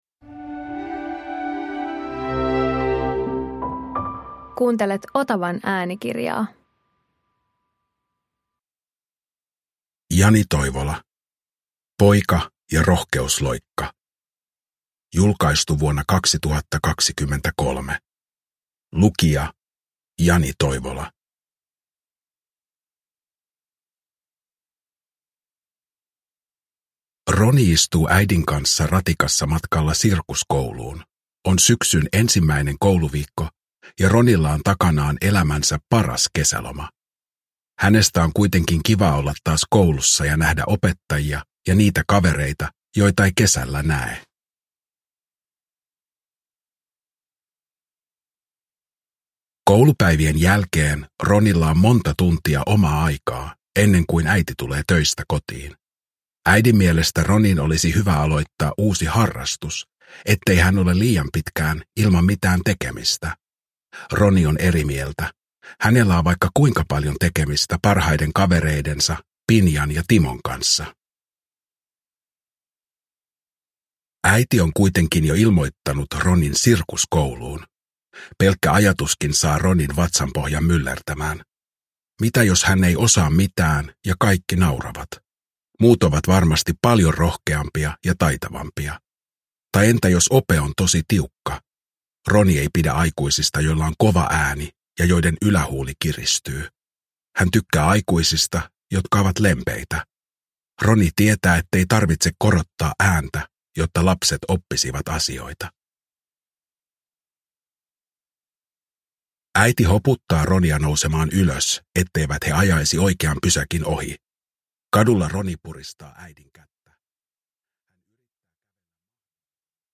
Poika ja rohkeusloikka – Ljudbok
Uppläsare: Jani Toivola